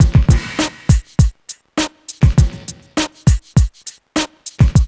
HF101BEAT1-L.wav